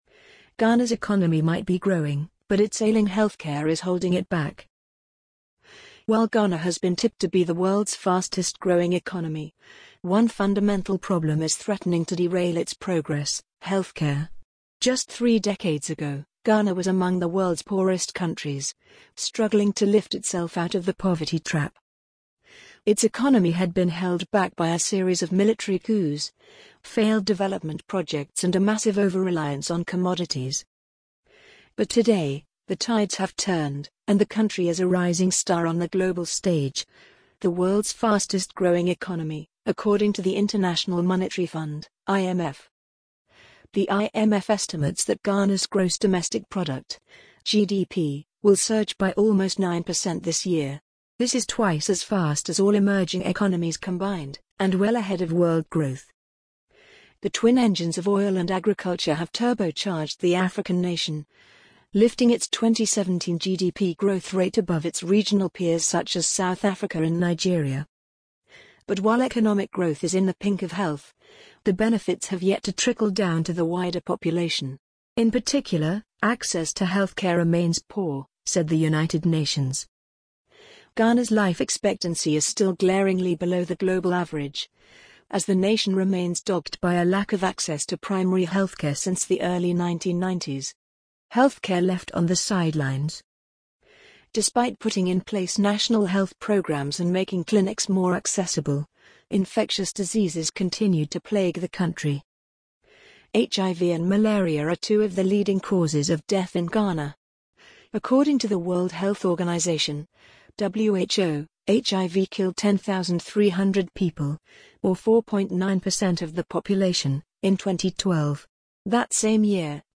amazon_polly_4893.mp3